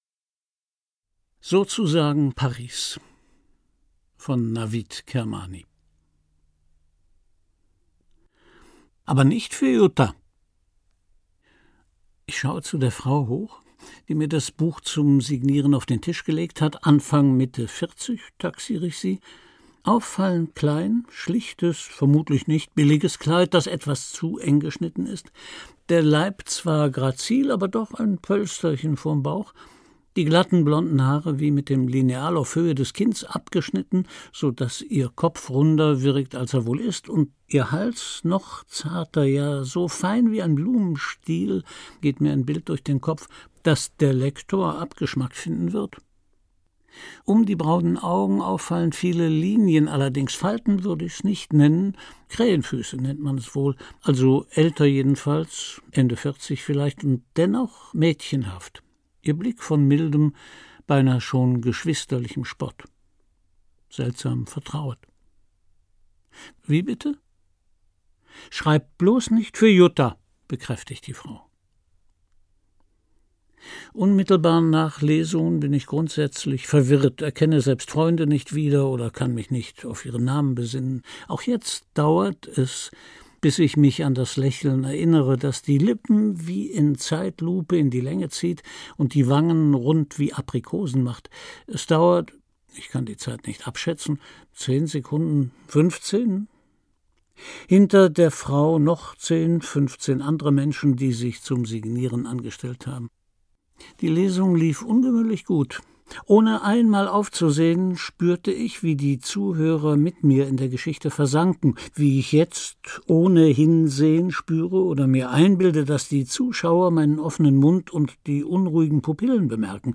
Christian Brückner (Sprecher)
Ungekürzte Lesung, 5 Audio-CDs, Laufzeit 5 Stunden 50 Minuten